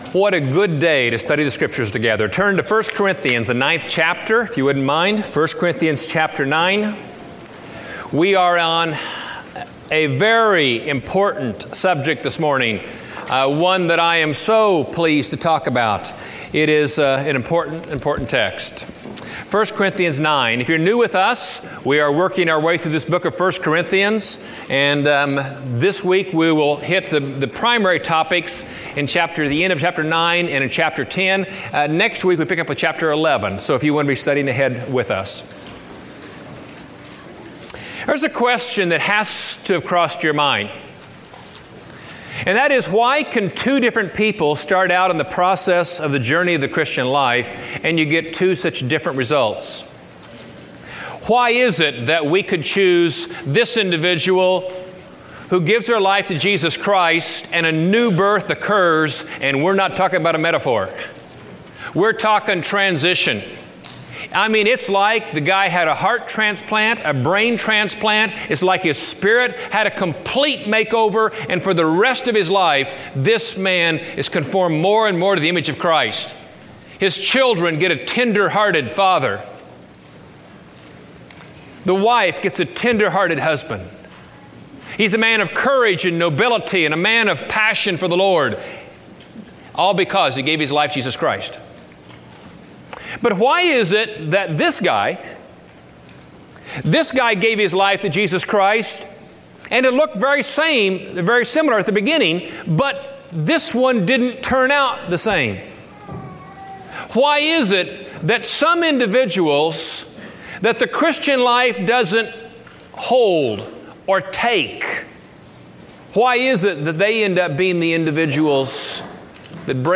Training in Light of Temptation Preached at College Heights Christian Church October 16, 2005 Series: 1 Corinthians 2005 Scripture: 1 Corinthians 10 Audio